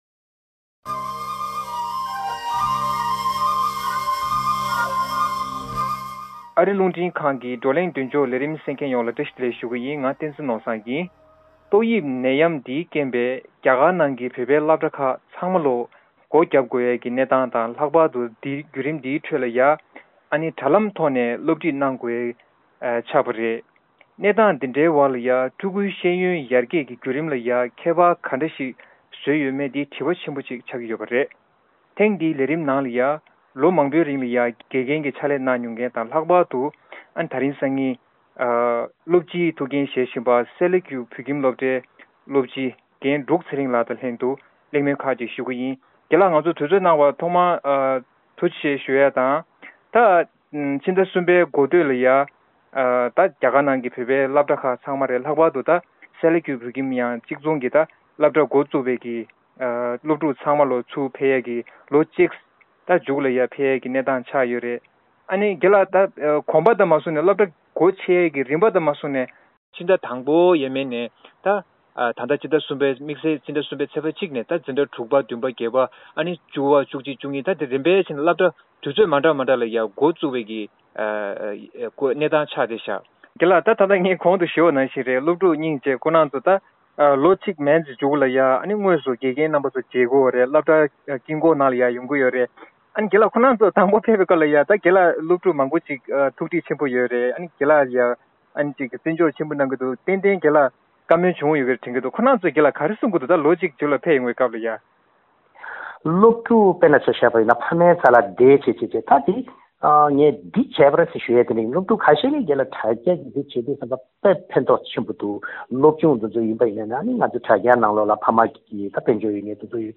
རྒྱ་གར་ནང་ཏོག་དབྱིབས་ནད་ཡམས་ཀྱི་གནས་སྟངས་ཏོག་ཙམ་དྲག་ཏུ་སོང་རྗེས་བོད་པའི་སློབ་གྲྭ་རྣམས་སླར་ཡང་སྒོ་ཕྱེད་ཡོད་ཅིང་། ཐེངས་འདིའི་བགྲོ་གླེང་མདུན་ཅོག་ལས་རིམ་གྱིས་སློབ་གྲྭ་དང་སློབ་ཕྲུག་བཅས་ཀྱིས་དོ་སྣང་དང་ཡིད་ཟབ་བྱེད་དགོས་གང་ཡོད་སྐོར་གླེང་མོལ་ཞུ་རྒྱུ་ཡིན།